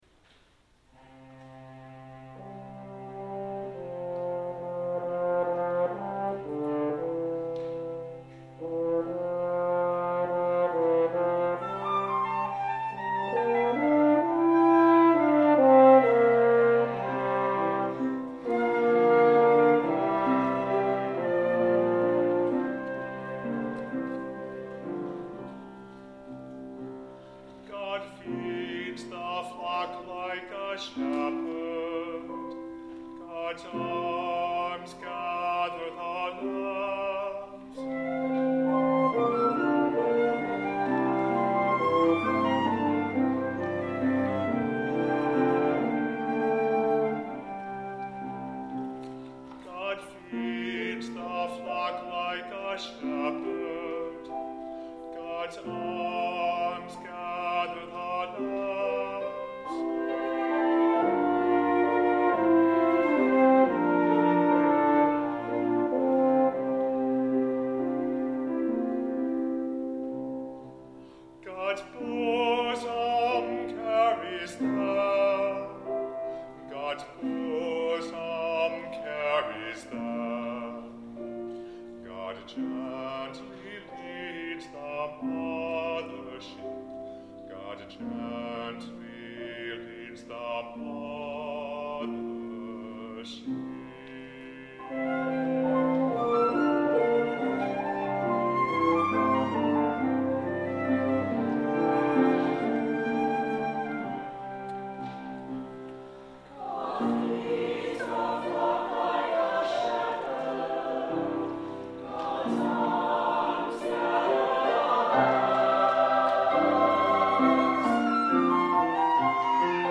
MP3 recordings are available for download below, as presented at the 9 a.m. and 11 a.m. services.
Bass Aria, Chorus
9 a.m. service (3.5 Mb)